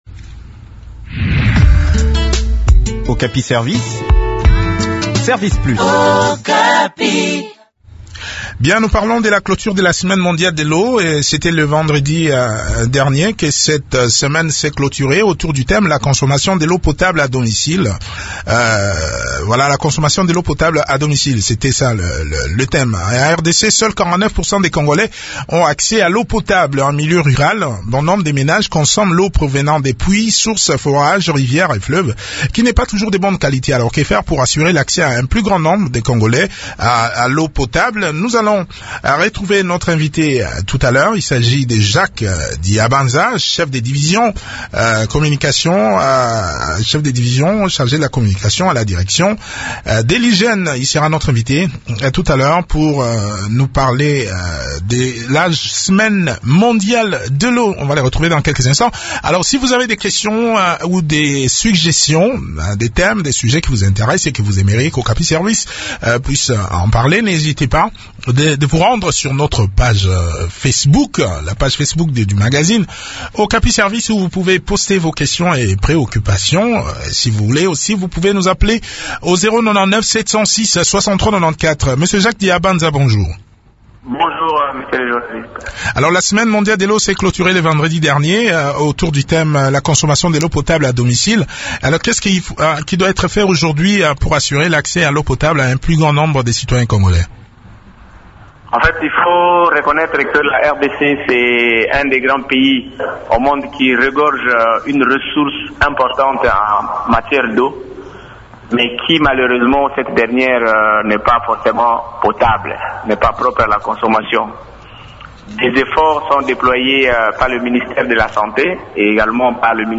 Le point de la situation dans cet entretien